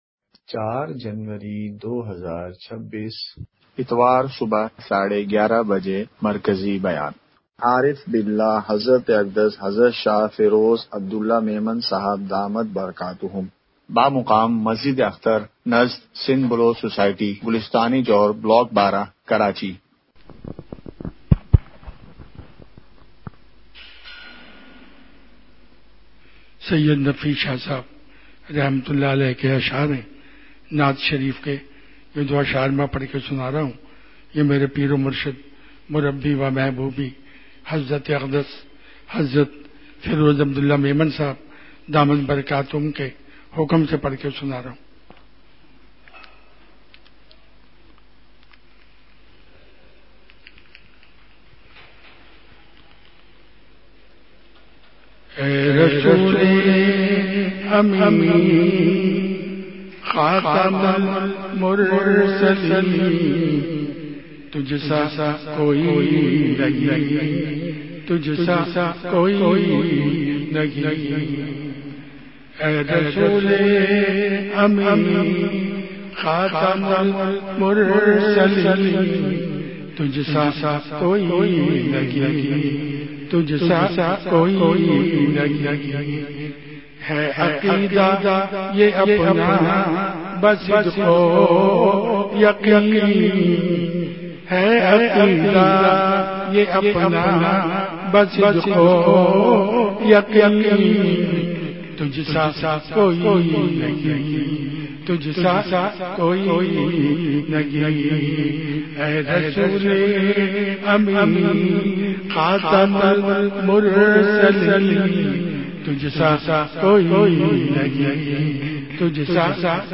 مقام:مسجدِ اختر نزدسندھ بلوچ سوسائٹی گلستانِ جوہر بلاک12کراچی
بیا ن کے آغاز میں اشعار کی مجلس ہوئی۔۔